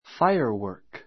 fáiə r wəː r k ふァ イアワ～ ク